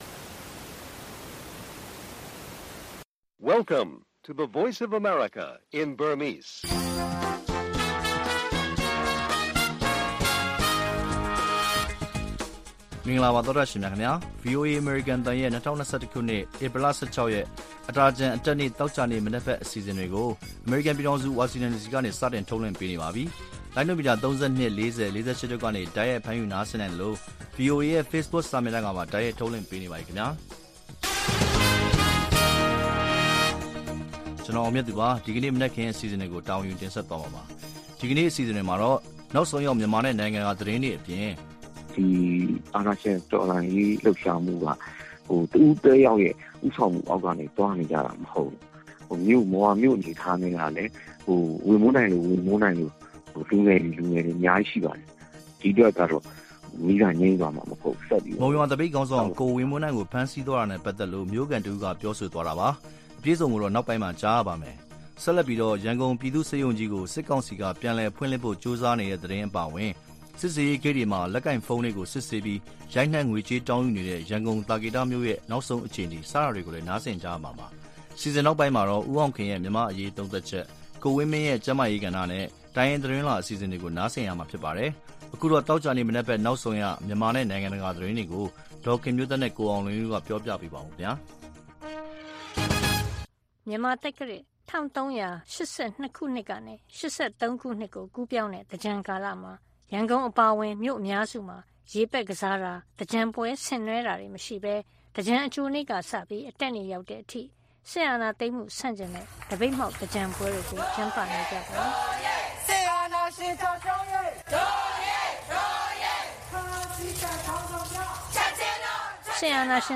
ဗွီအိုအေ ရေဒီယိုအစီအစဉ် တိုက်ရိုက် ထုတ်လွှင့်မှု ဗွီအိုအေရဲ့ သောကြာနေ့ မနက်ပိုင်း ရေဒီယိုအစီအစဉ်ကို ရေဒီယိုကနေ ထုတ်လွှင့်ချိန်နဲ့ တပြိုင်နက်ထဲမှာပဲ Facebook ကနေလည်း တိုက်ရိုက် ထုတ်လွှင့်ပေးနေပါတယ်။